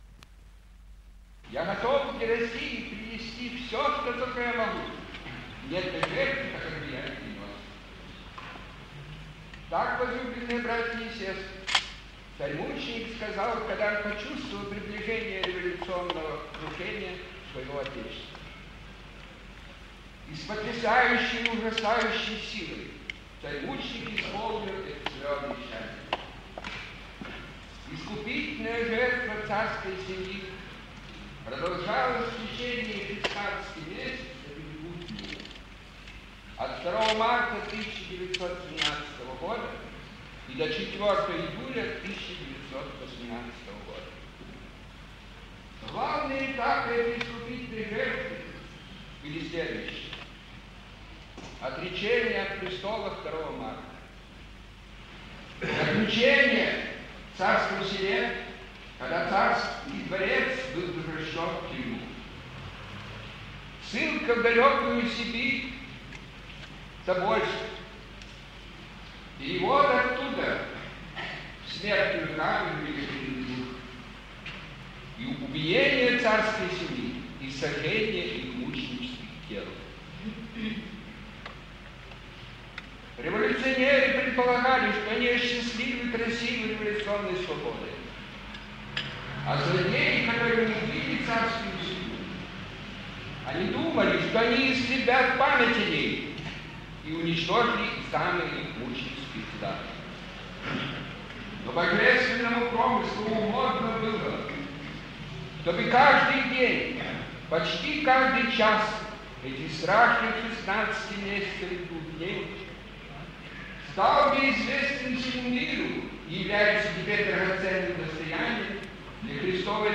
Торжественное отпевание Царской семьи и всех жертв коммунистического террора в храме-памятнике в Брюсселе 17 июля 1968 г.
Архиепископы Никон Вашингтонский и Флоридский и Антоний Женевский и Западно – Европейский, епископы Нафанаил и Павел Штутгарский сослужили соборне, окруженные многочисленным духовенством. Чин заочного отпевания был совершен перед Чудотворной Иконой Божией Матери Курской – Коренной.
royalfamily_funeral.mp3